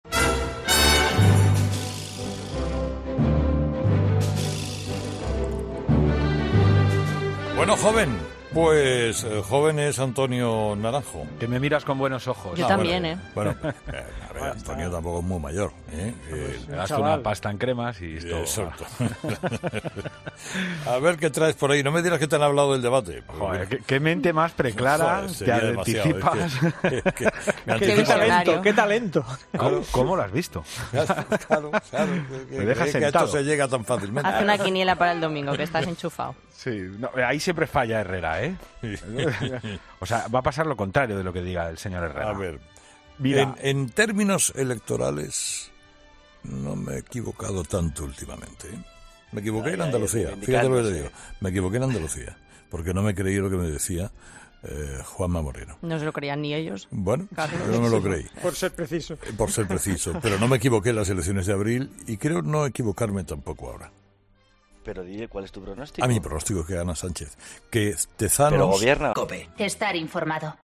Avalancha de mensajes en el contestador de ‘Herrera en COPE’ con los temas del día: el debate electoral y la visita de los Reyes a Cataluña.